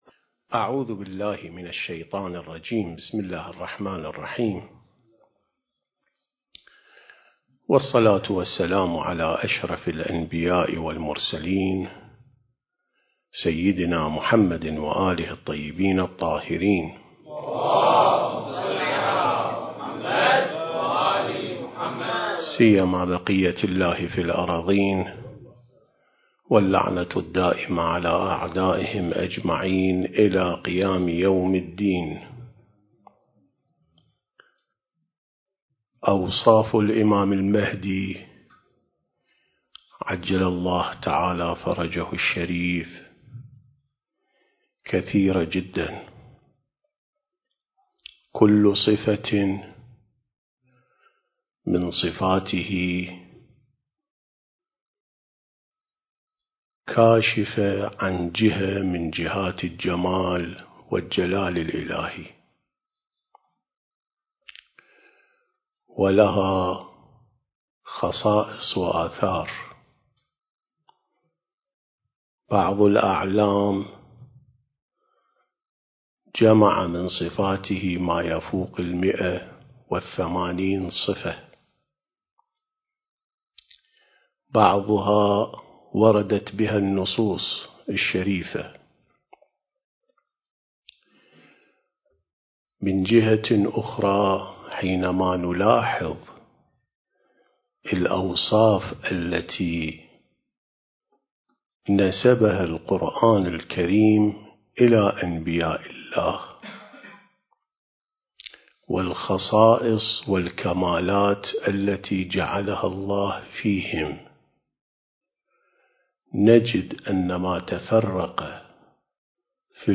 سلسلة محاضرات عين السماء ونهج الأنبياء